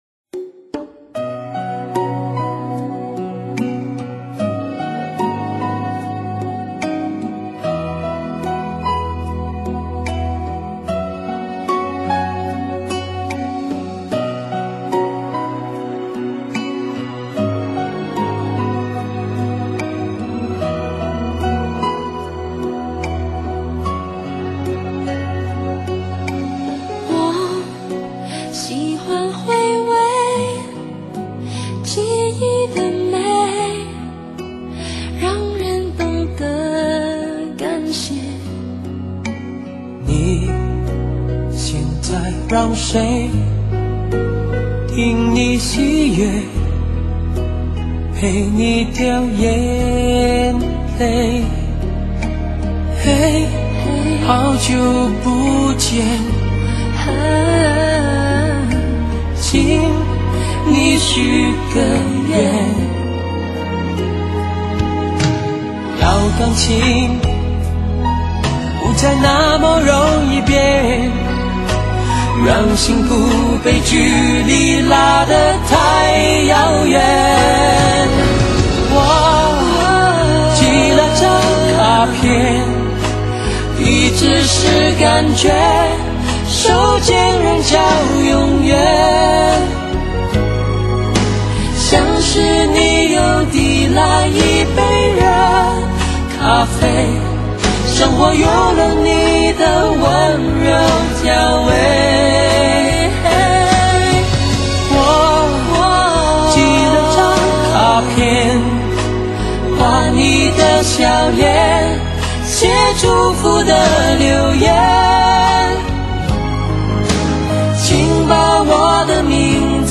深情对唱的